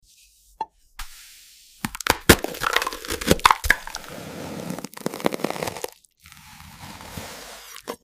🔪🍉 Knife vs frozen watermelon… who you got? This slice felt illegal 😮‍💨💥 No music. Just raw sounds and frozen fruit.”